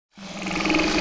TM88 BubblesFX.wav